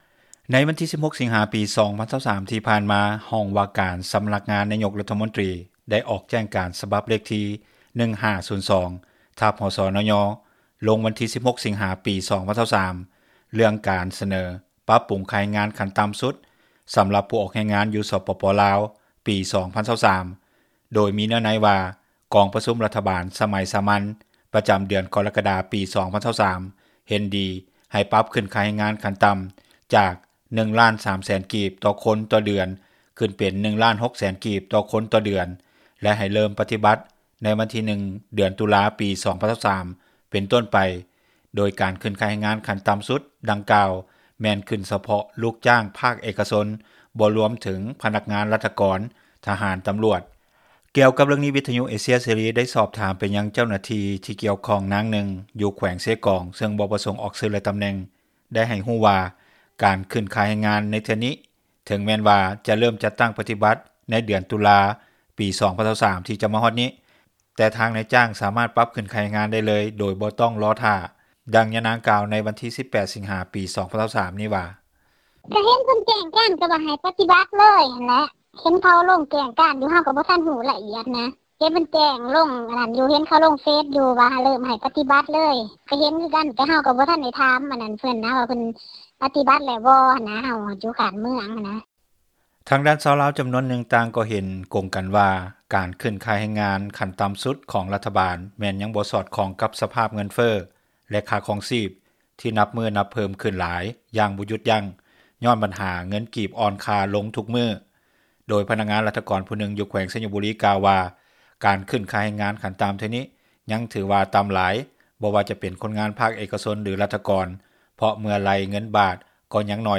ກ່ຽວກັບເຣື່ອງນີ້, ວິທຍຸ ເອເຊັຽ ເສຣີ ໄດ້ສອບຖາມເຈົ້າໜ້າທີ່ກ່ຽວຂ້ອງ ນາງນຶ່ງ ຢູ່ແຂວງເຊກອງເຊິ່ງບໍ່ປະສົງອອກຊື່ ແລະຕຳແໜ່ງ ໄດ້ກ່າວວ່າການຂຶ້ນຄ່າແຮງງານ ໃນເທື່ອນີ້ ເຖິງແມ່ນວ່າຈະເຣີ່ມຈັດຕັ້ງປະຕິບັດ ໃນເດືອນຕຸລາ 2023 ທີ່ຈະມາຮອດນີ້ ແຕ່ທາງນາຍຈ້າງ ສາມາດປັບຂຶ້ນຄ່າແຮງງານໄດ້ເລີຍ ໂດຍບໍ່ຕ້ອງລໍຖ້າ.